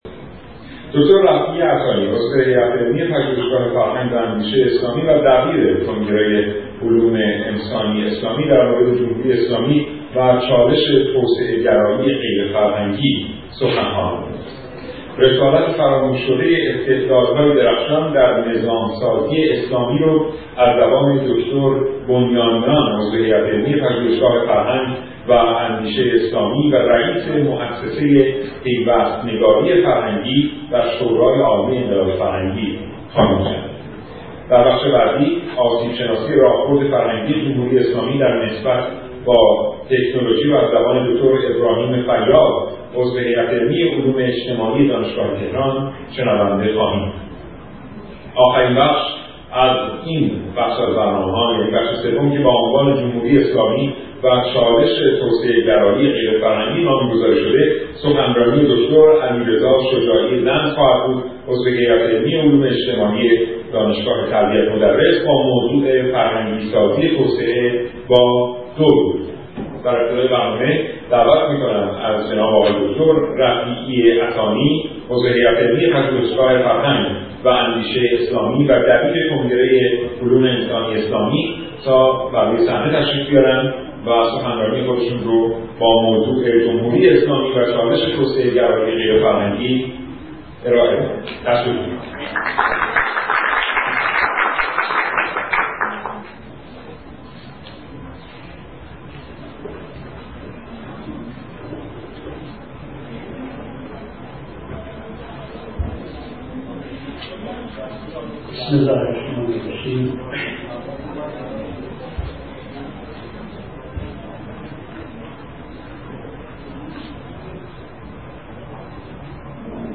سخنرانی
در اولین همایش ملی فرهنگ و تکنولوژی